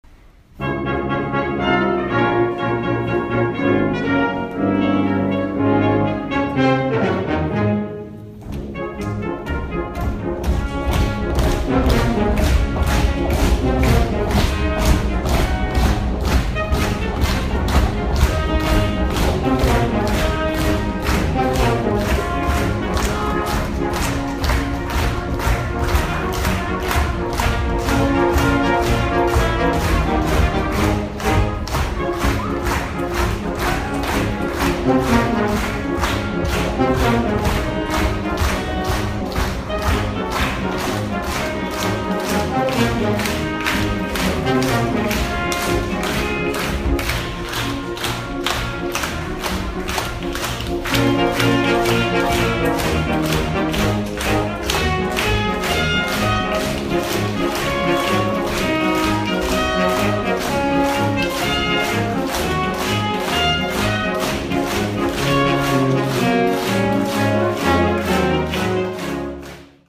東京藝術大学の学生６名が来校し、金管ア ンサンブルをきかせてもらいました。